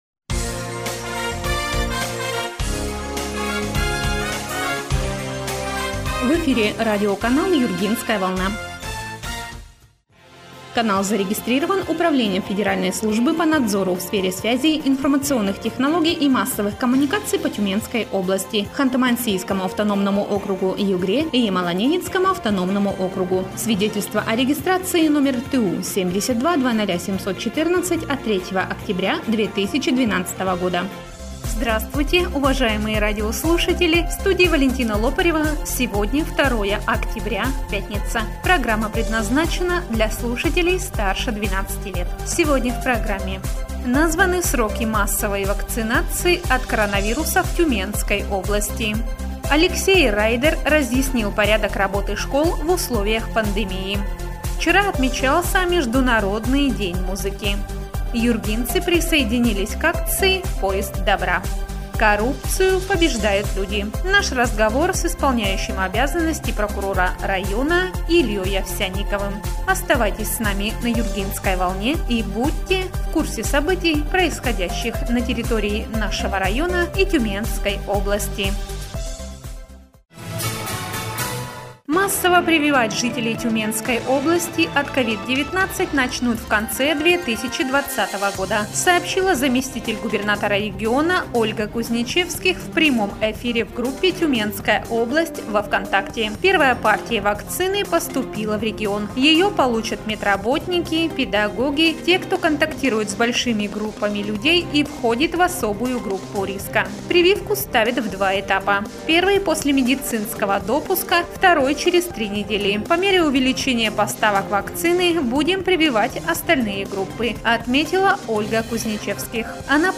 Эфир радиопрограммы "Юргинская волна" от 2 октября 2020 года.